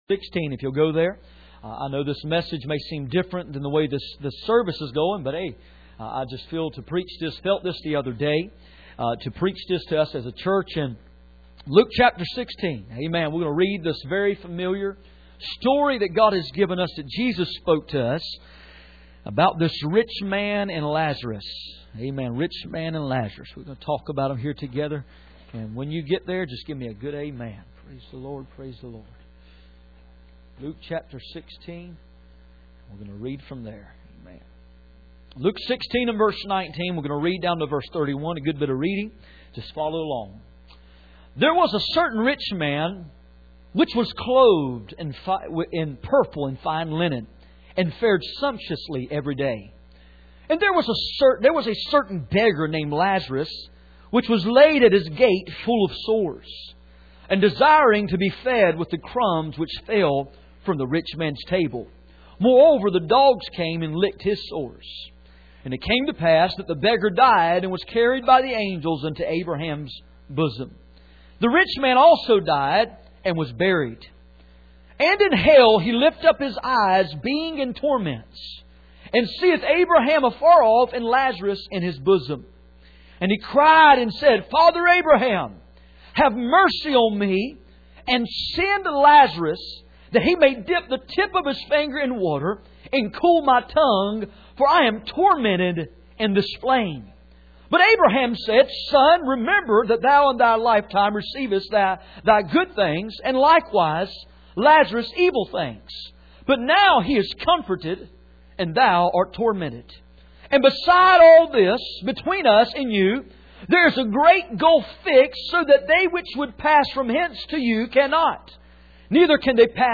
None Passage: Luke 16:20-31 Service Type: Sunday Morning %todo_render% « The proofs of HIS presence